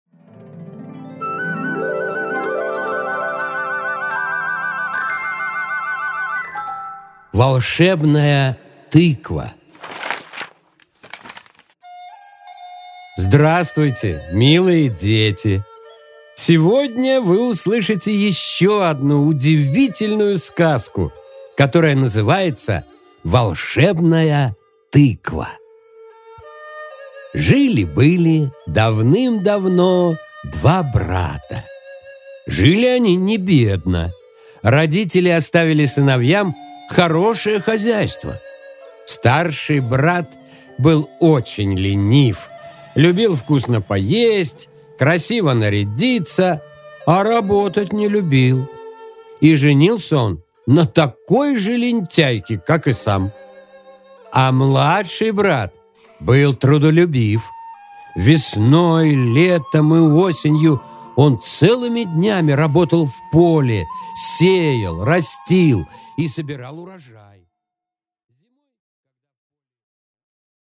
Аудиокнига Волшебная тыква